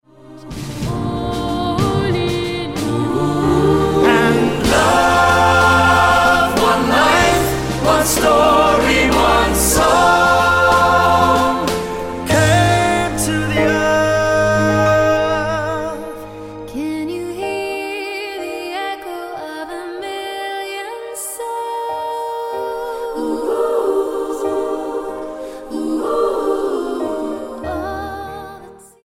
Pop Single
Style: Pop